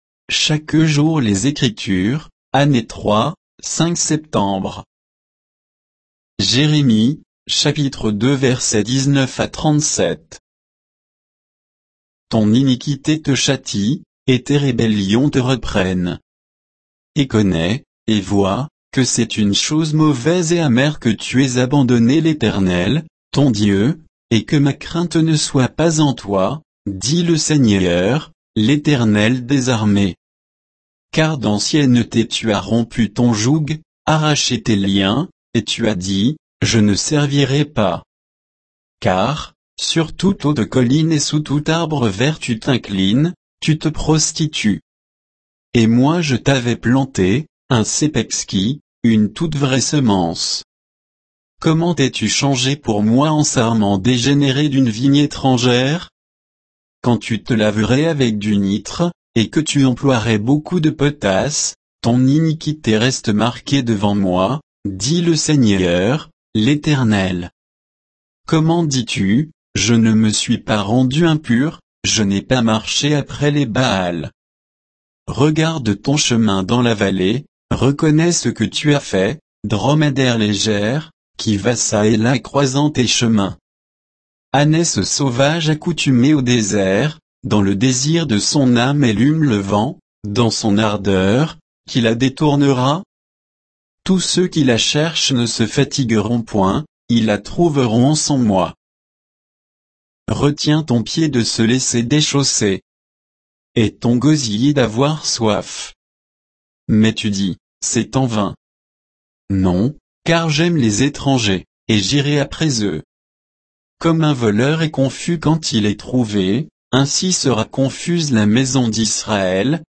Méditation quoditienne de Chaque jour les Écritures sur Jérémie 2